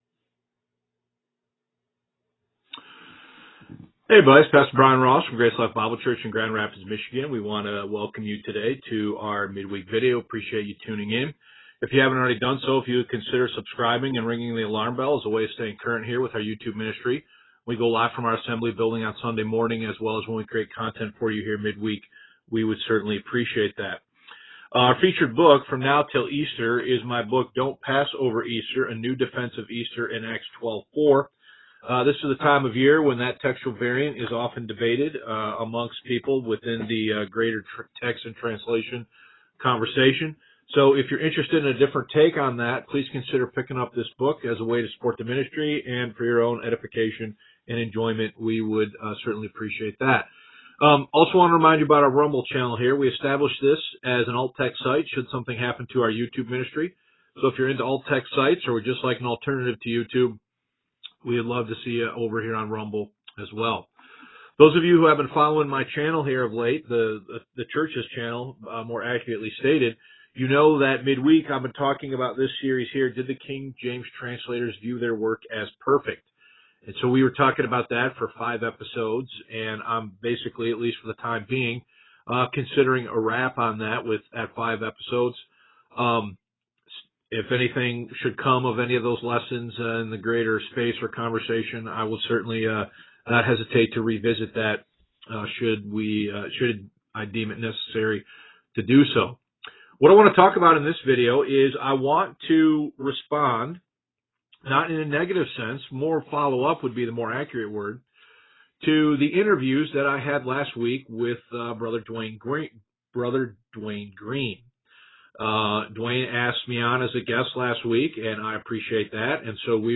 Guest Appearances & Interviews